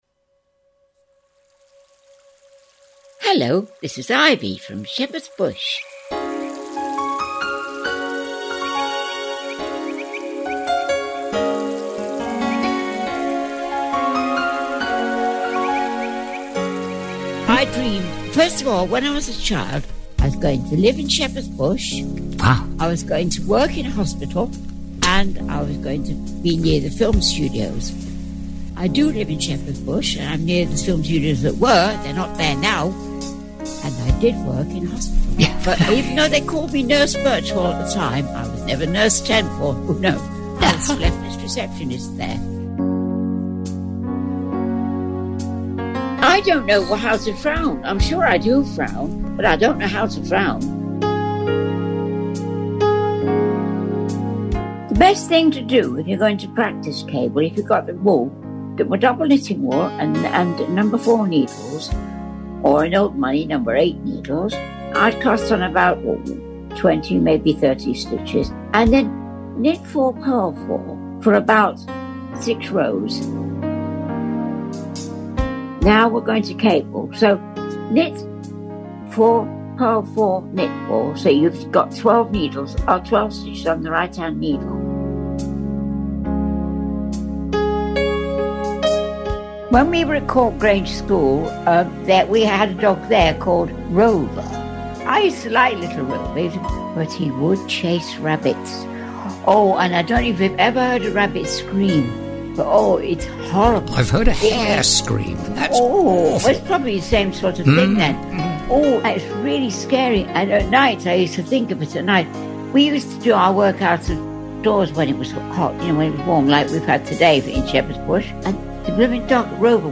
The All Sorts team have produced an audio tribute in her memory.